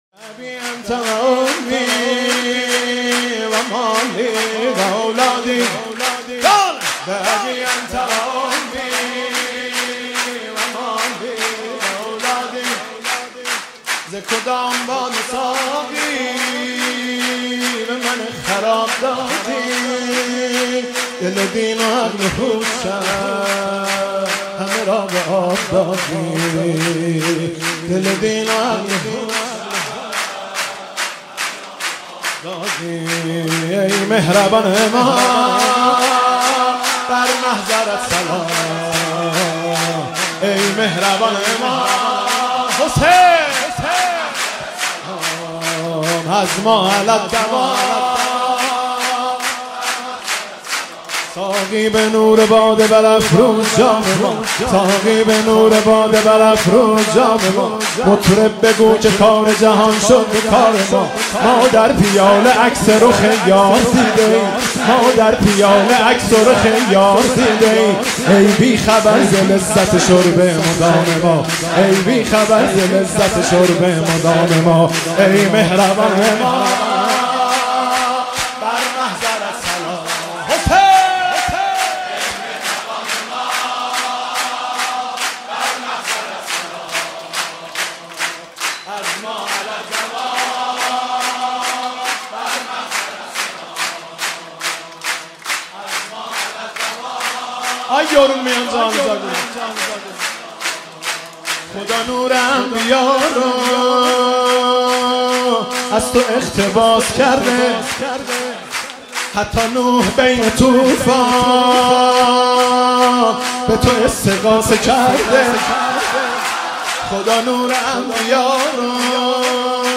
مولودی بسیار شاد و خوش طنین